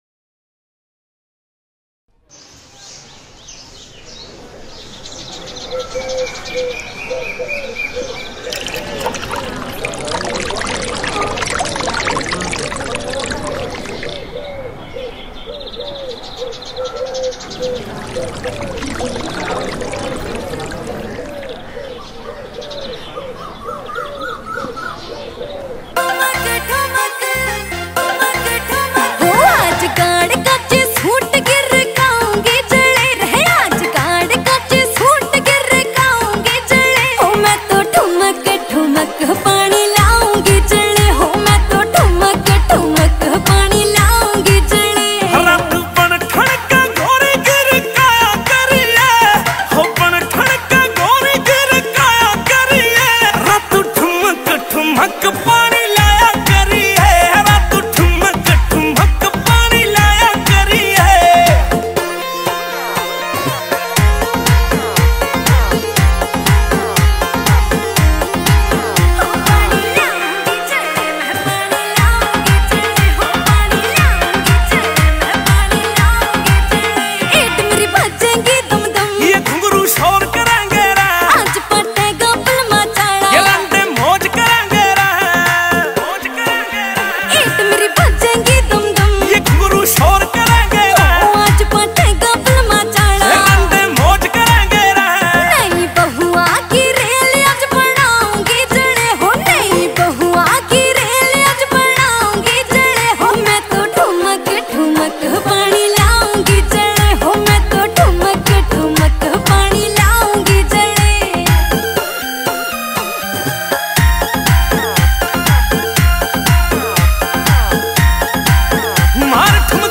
New Haryanvi Mp3 Songs 2025